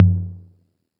808 L Tom  5.wav